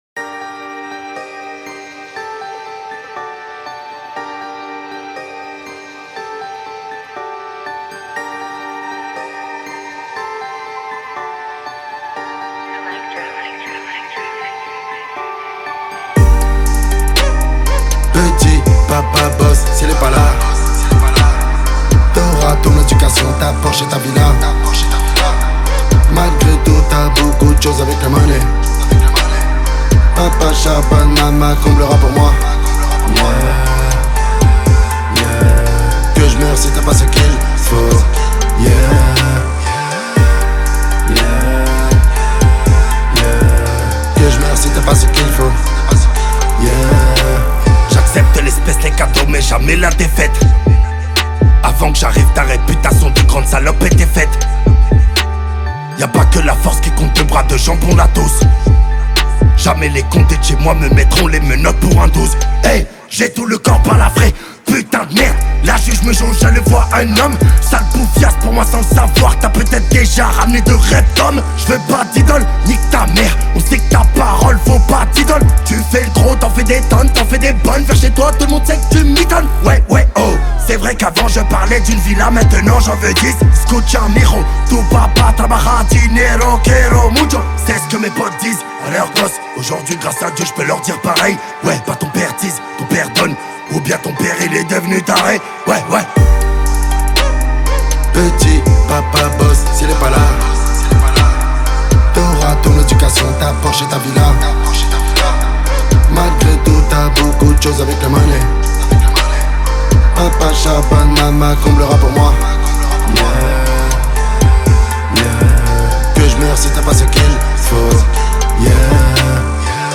0/100 Genres : raï Écouter sur Spotify